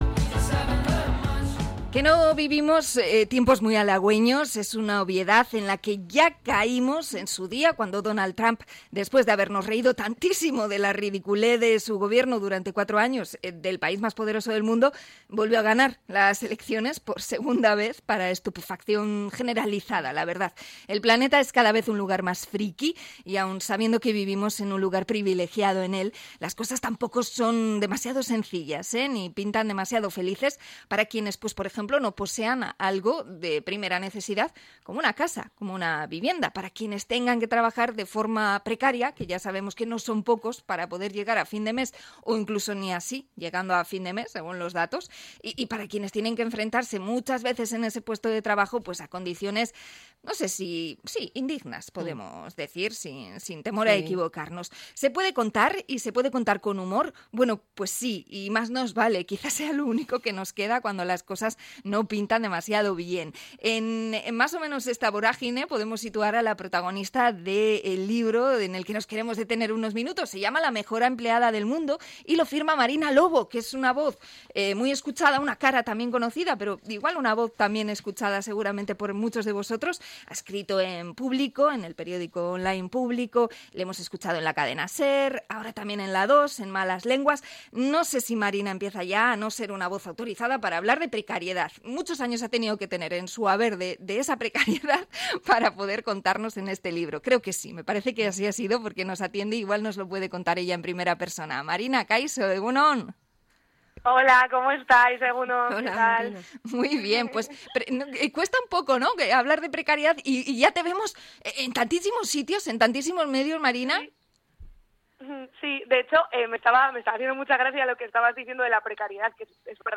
Entrevista a la periodista y humorista Marina Lobo
INT.-MARINA-LOBO.mp3